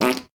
epic_bellow_01.ogg